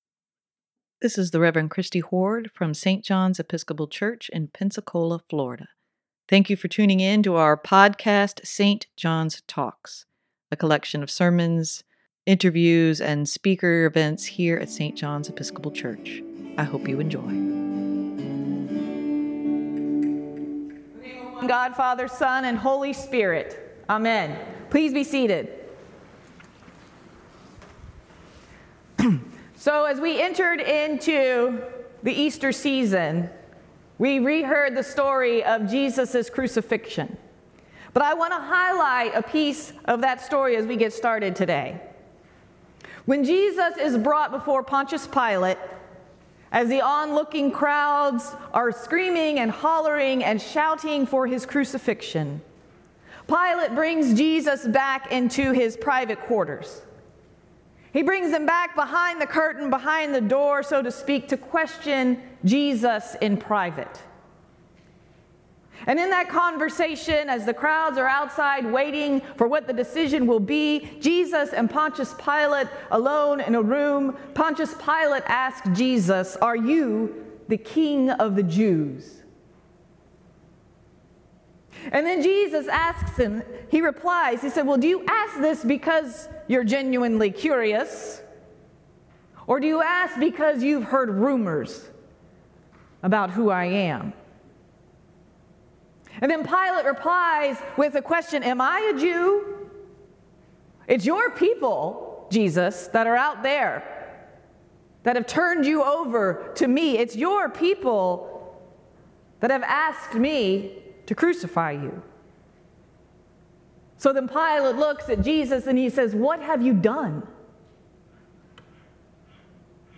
sermon-5-14-23.mp3